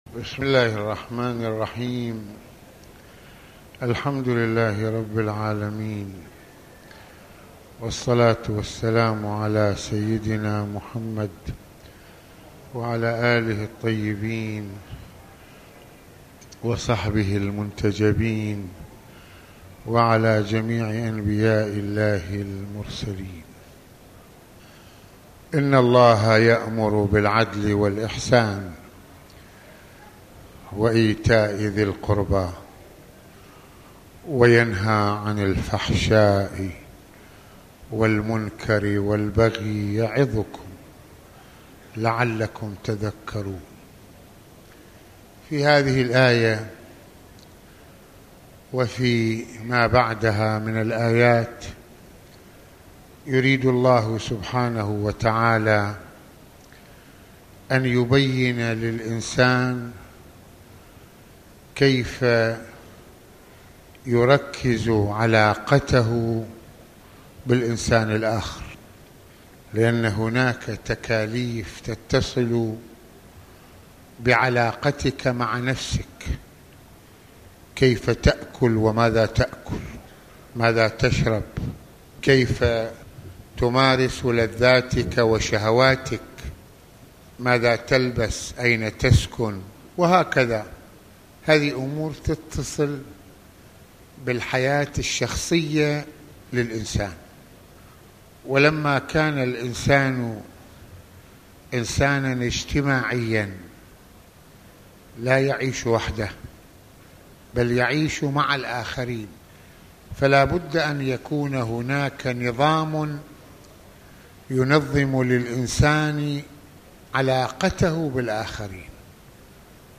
- المناسبة : موعظة ليلة الجمعة المكان : مسجد الإمامين الحسنين (ع) المدة : 22د | 34ث التصنيف : عقائد المواضيع : كيف نظم الله حياة الانسان - الحقوق الزوجية - احترام تعب الزوجة - حاجات الزوجة الجنسية - الظلم في المجتمع